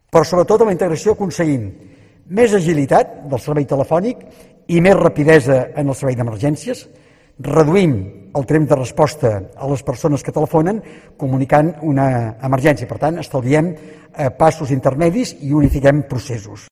Albert Batlle explica las ventajas de unificar el teléfono de emergencias en el 112